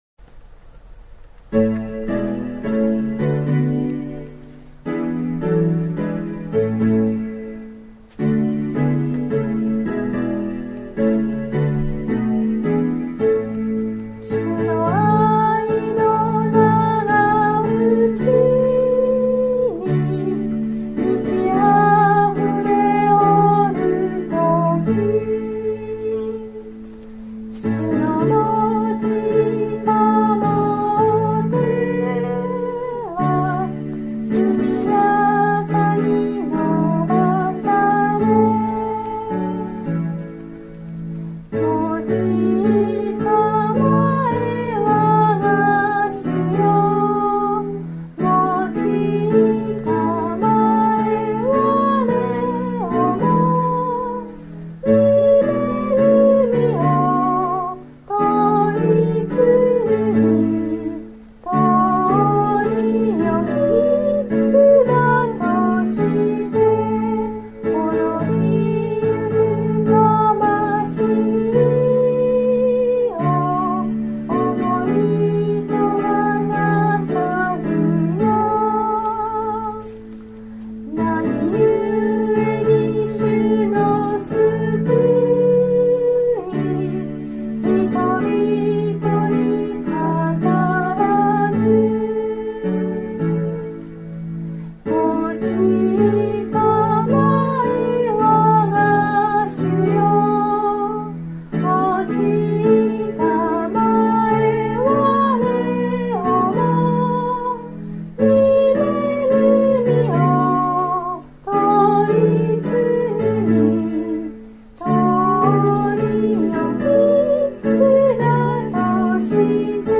God bless you）より 唄
（徳島聖書キリスト集会集会員）